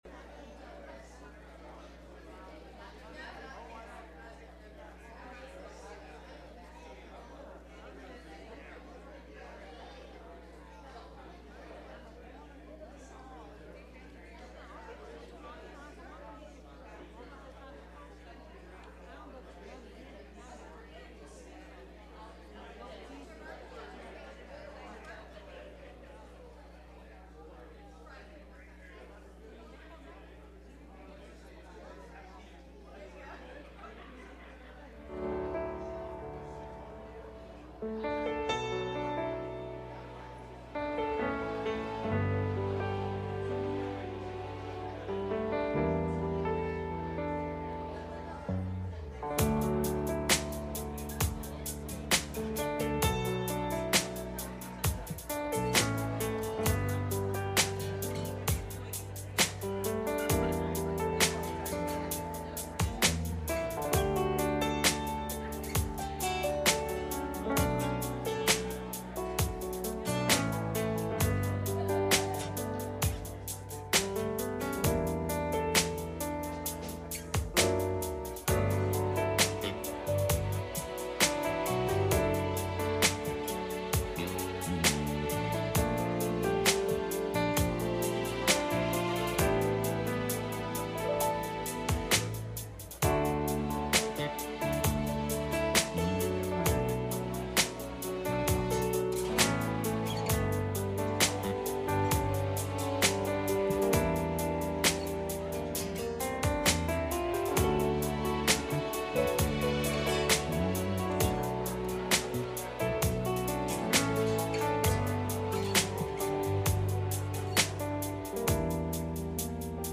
Matthew 8:10-26 Service Type: Sunday Morning « The Ministry Of Jesus